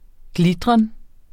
Udtale [ ˈglidʁʌn ]